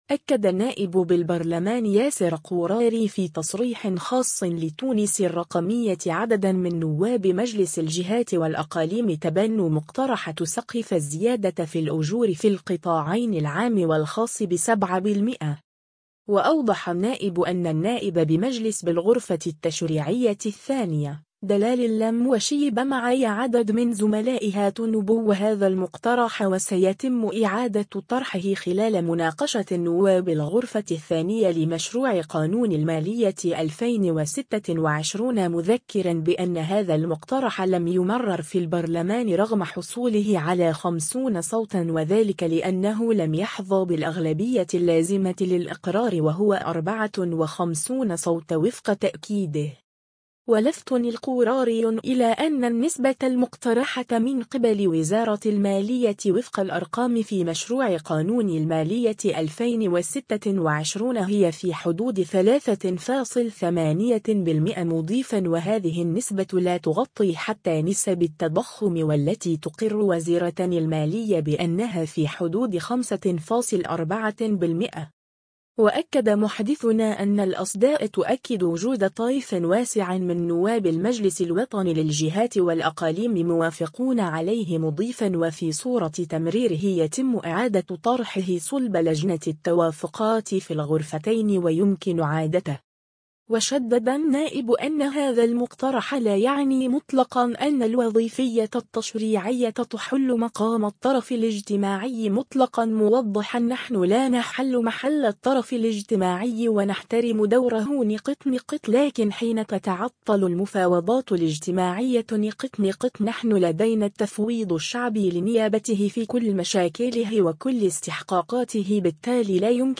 أكد النائب بالبرلمان ياسر قوراري في تصريح خاص لـ”تونس الرقمية” عددا من نواب مجلس الجهات والأقاليم تبنوا مقترح تسقيف الزيادة في الأجور في القطاعين العام والخاص بـ7%.